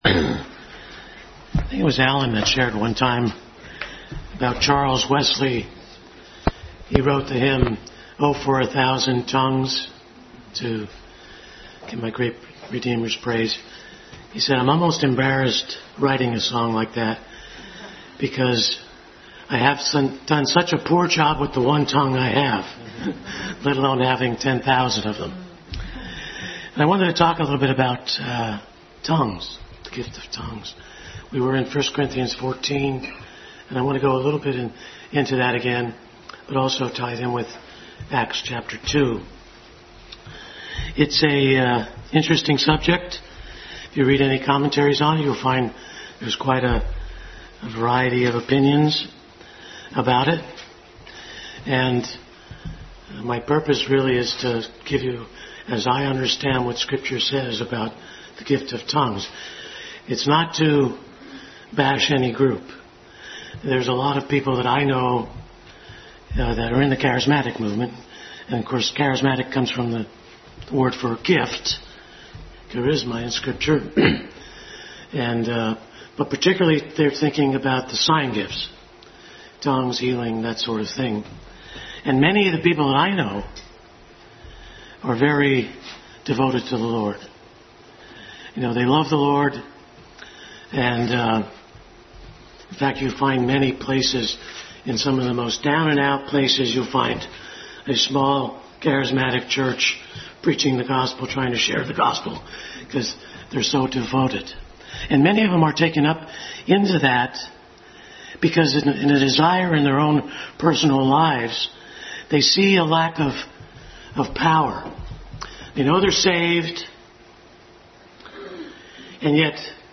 Adult Sunday School Class continued study in 1 Corinthians.